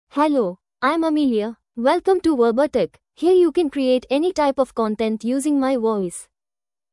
Amelia — Female English (India) AI Voice | TTS, Voice Cloning & Video | Verbatik AI
Amelia is a female AI voice for English (India).
Voice sample
Female
Amelia delivers clear pronunciation with authentic India English intonation, making your content sound professionally produced.